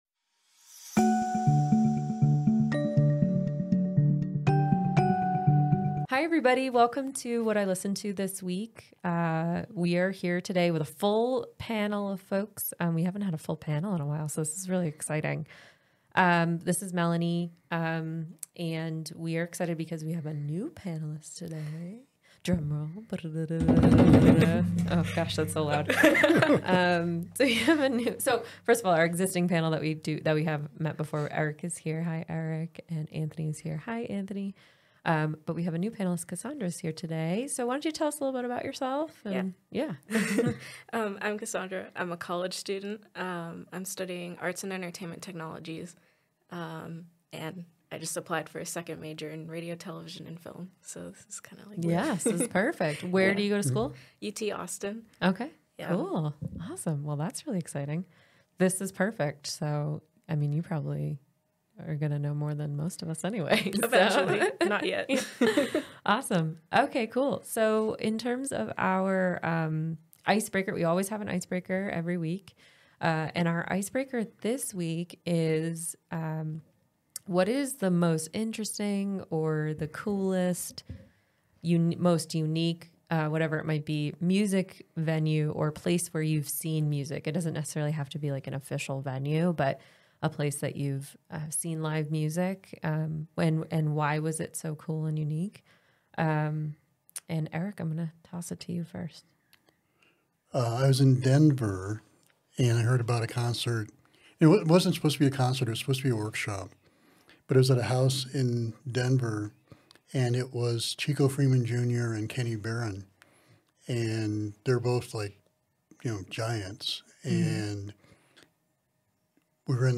In this episode, our panelists delve into each track, offering insightful discussions and passionate reflections that will ignite your passion for music.